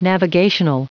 Prononciation du mot navigational en anglais (fichier audio)
Prononciation du mot : navigational